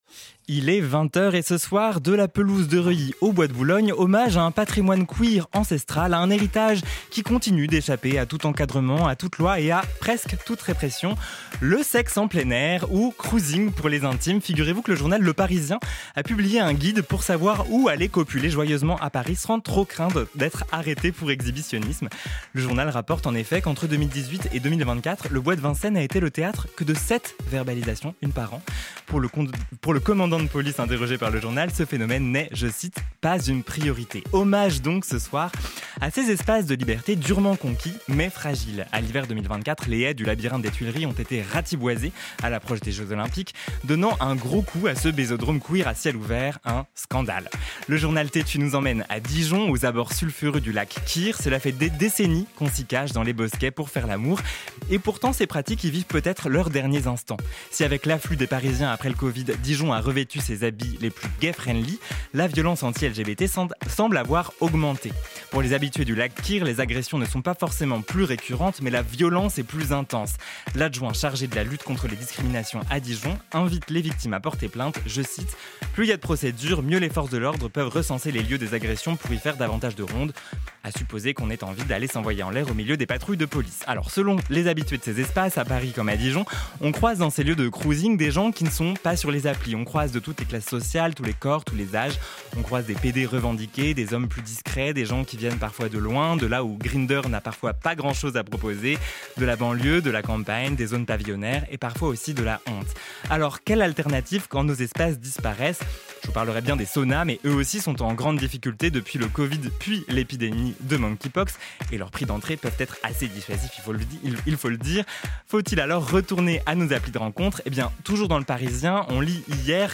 Magazine